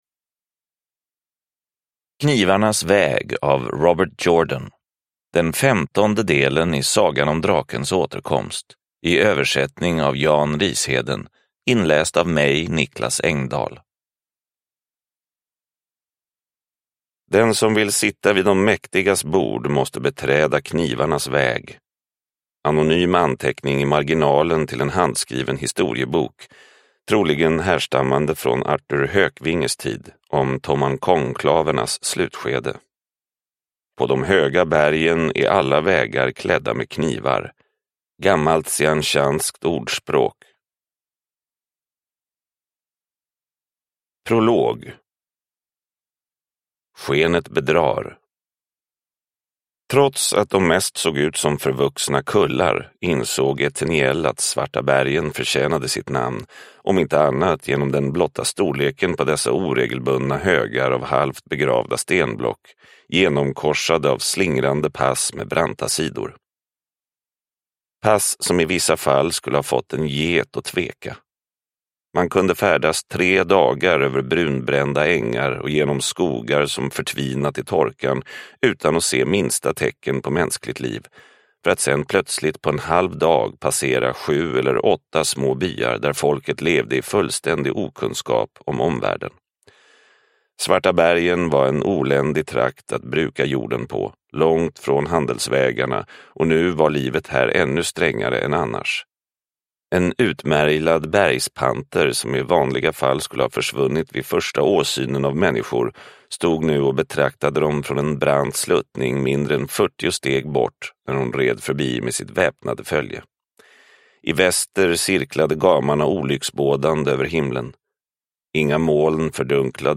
Knivarnas väg – Ljudbok – Laddas ner